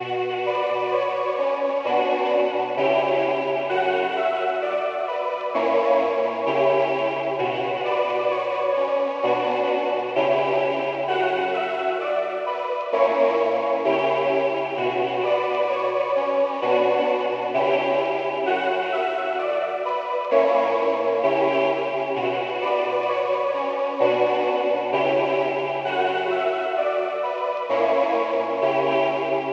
黑暗合唱团
描述：方舟合唱团在FL studio 12中制作。
Tag: 120 bpm Trap Loops Choir Loops 2.69 MB wav Key : Unknown